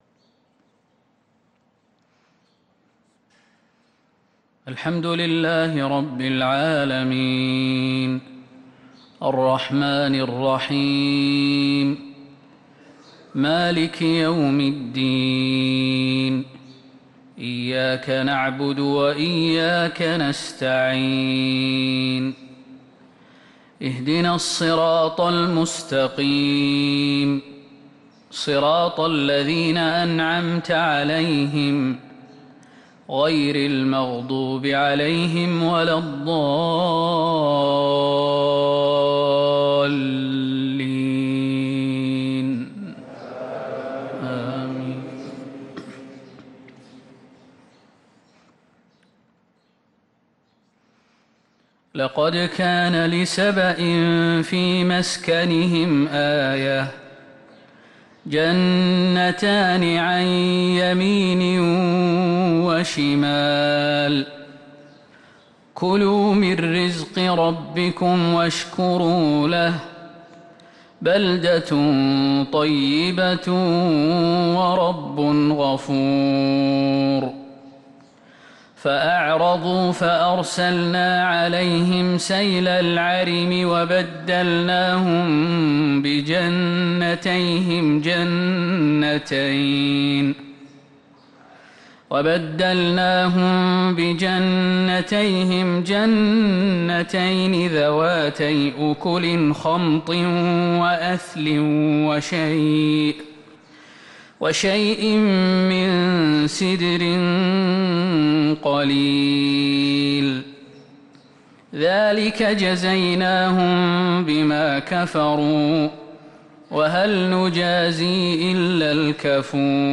صلاة الفجر للقارئ خالد المهنا 19 صفر 1445 هـ
تِلَاوَات الْحَرَمَيْن .